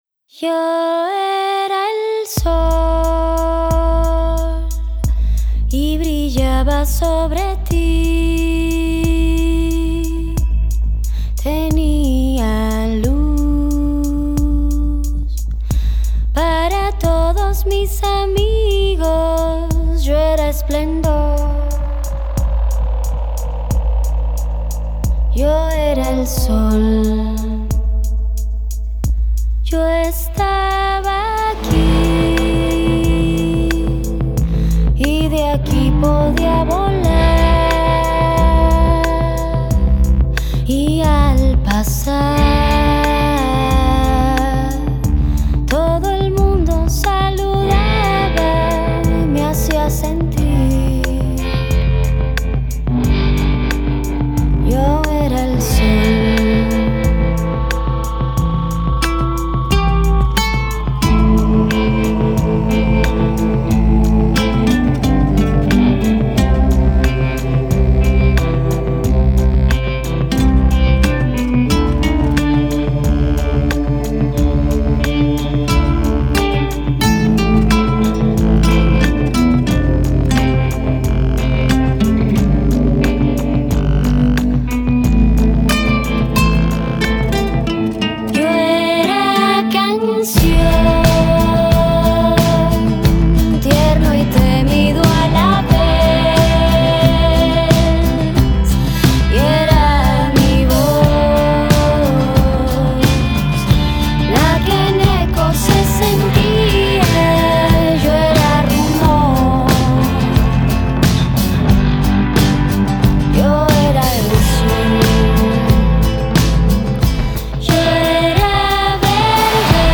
Guitarra y voz
Bajo
Batería
Cellos
Violines